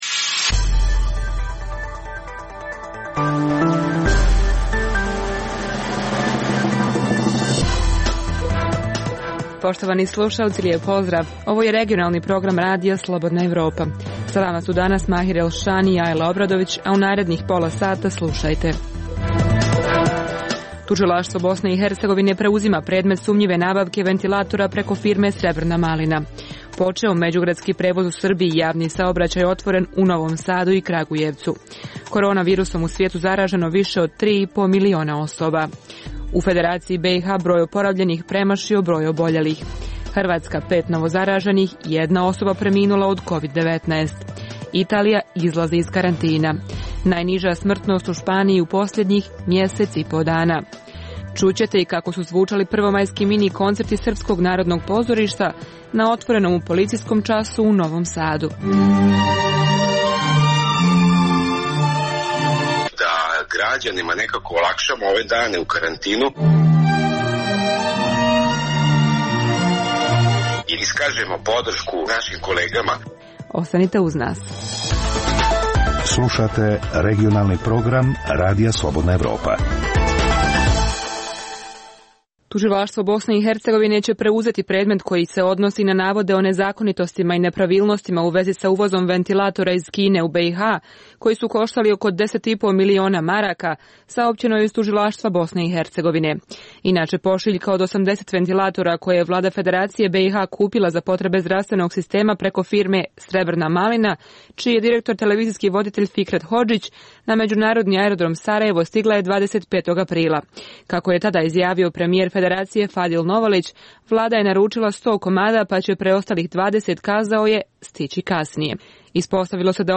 Dnevna informativna emisija Radija Slobodna Evropa o događajima u regionu i u svijetu. Vijesti, teme, analize i komentari. Tužilaštvo BiH preuzima predmet sumnjive nabavke ventilatora preko firme Srebrna malina.